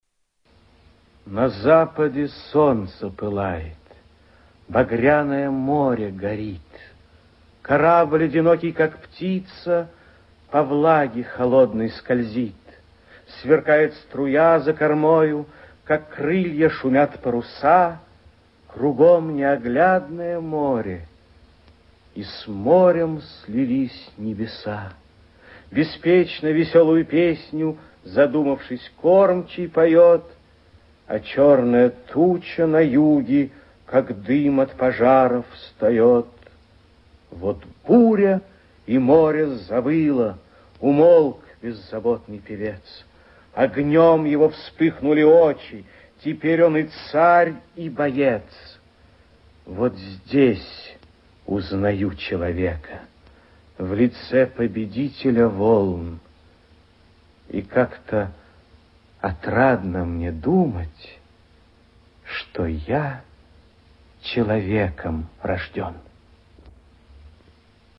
Прослушивание аудиозаписи стихотворения с сайта «Старое радио»